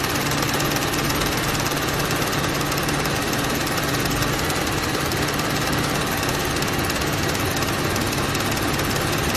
projector.mp3